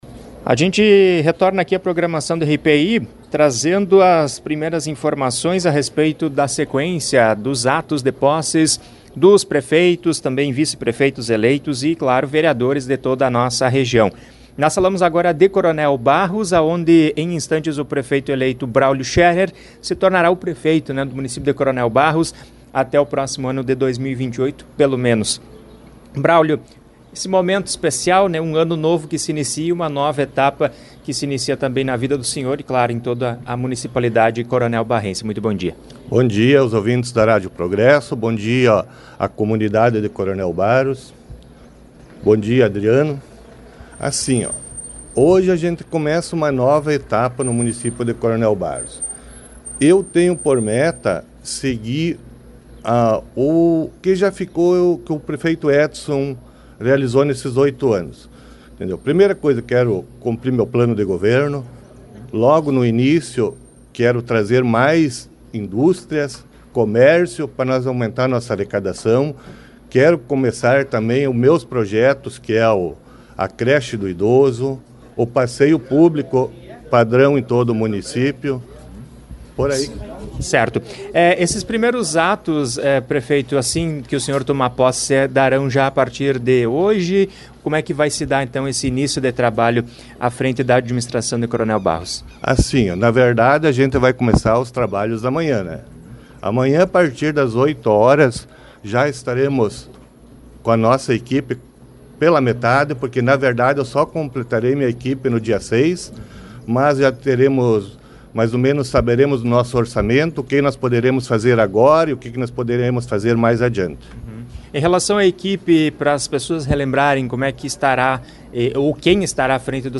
Em Coronel Barros os vereadores, prefeito e vice foram empossados na manhã desta quarta-feira, 1º de janeiro, em solenidade realizada no Centro Municipal de Cultura.
Em entrevista à RPI, o prefeito destacou as primeiras ações que buscará realizar a partir desta quinta-feira, 02, e elencou alguns dos principais objetivos contemplados no plano de governo.